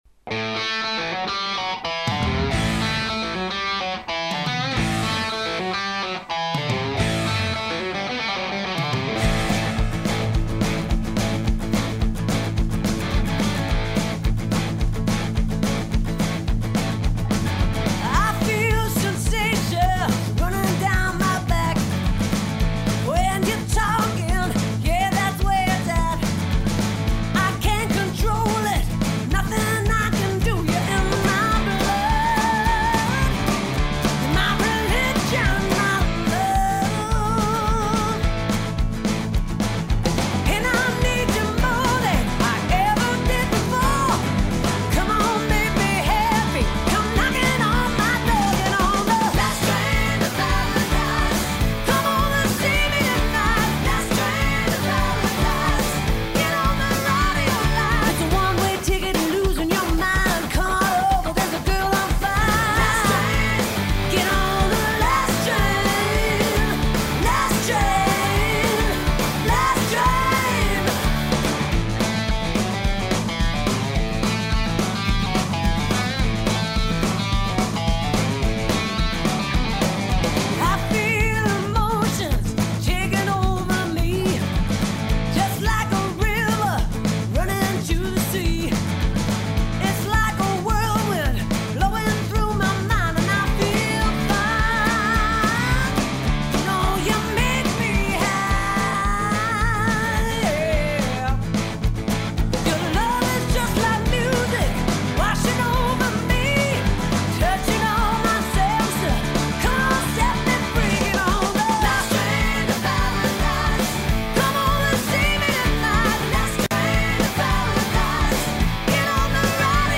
Style: Blues Rock, Soft Rock, Symphonic Rock
1. "Снятый" звук одной из композиций с этого альбома: